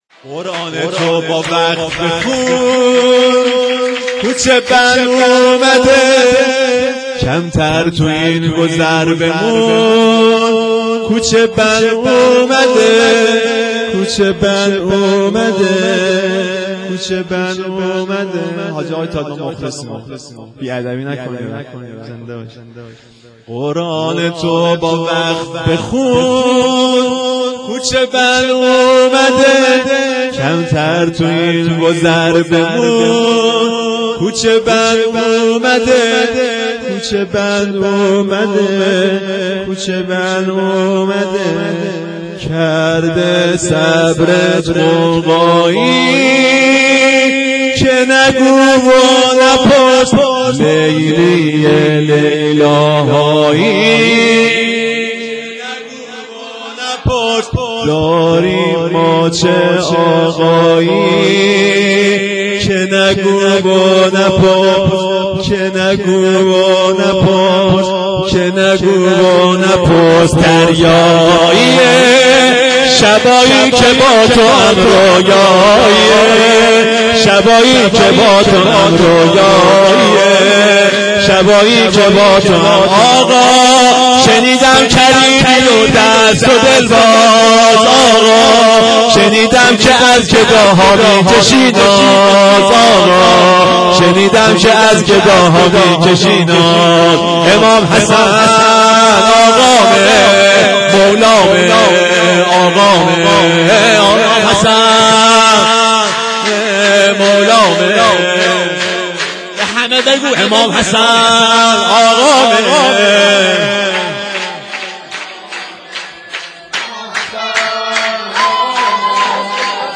شام میلاد امام حسن - رمضان 1392